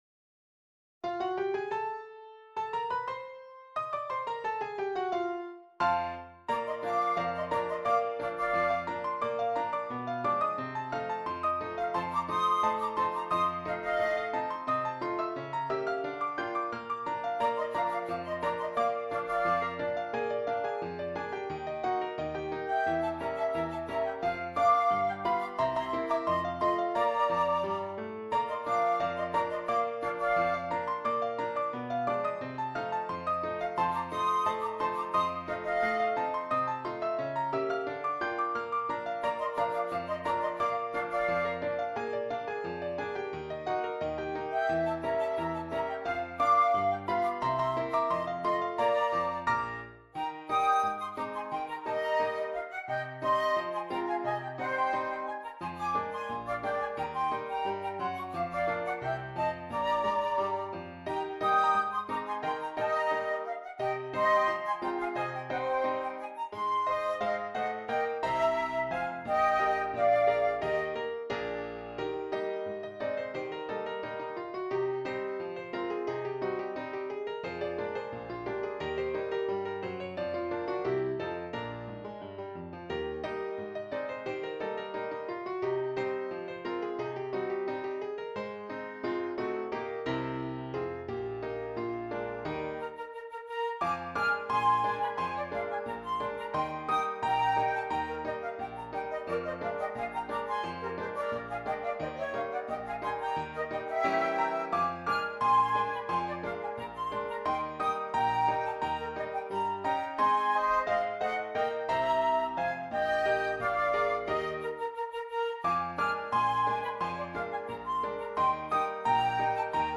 2 Flutes and Keyboard